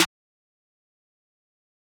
SizzSnr1.wav